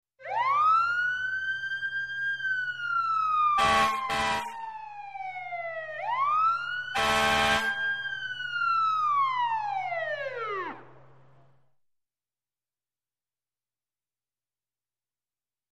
FireTruckSirenClos PE081501
Fire Truck Siren And Close Perspective Horn Honks, Off At Tail.